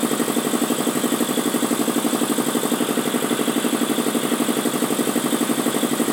sounds_compressor_motor_02.ogg